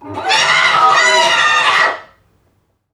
NPC_Creatures_Vocalisations_Robothead [78].wav